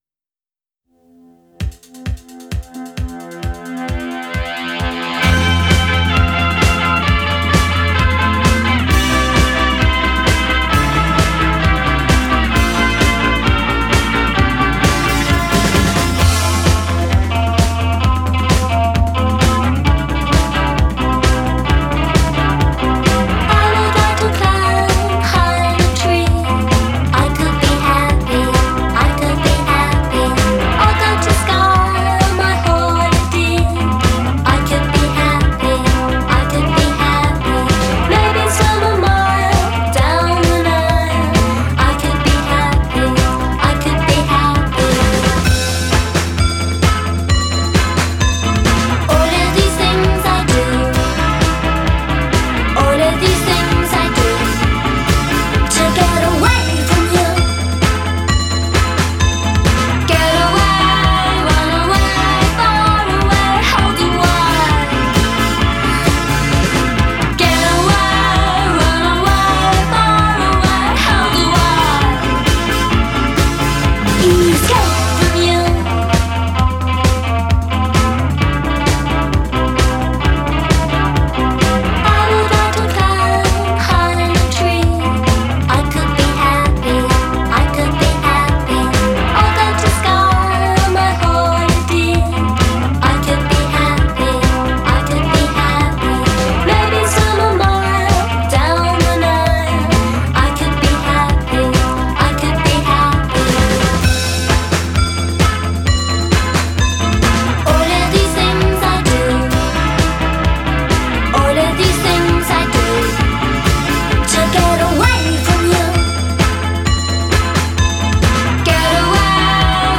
absurdly infectious